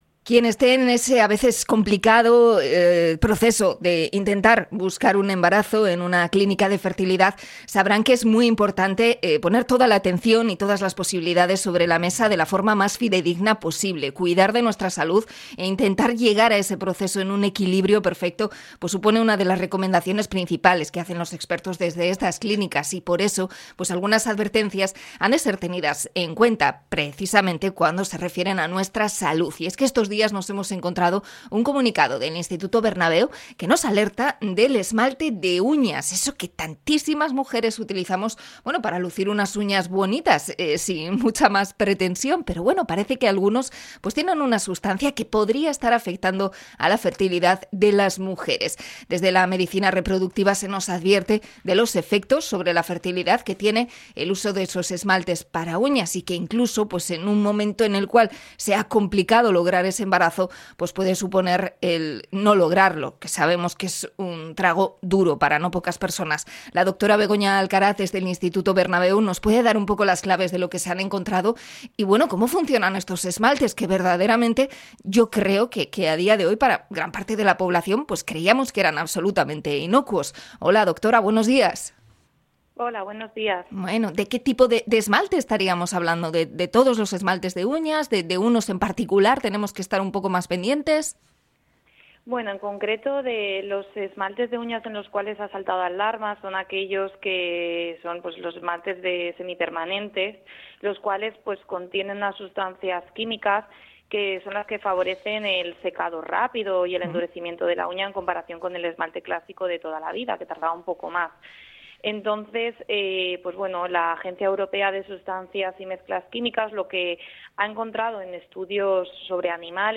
Entrevista a clínica de fertilidad por el uso de uñas permanentes